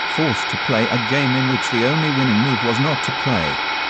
Vocoder-Ready stereo file.
(Text-to-speech vocal in the left channel, paulstretched sample in the right channel.)